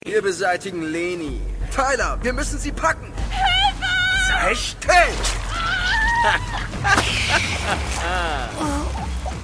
Sprachproben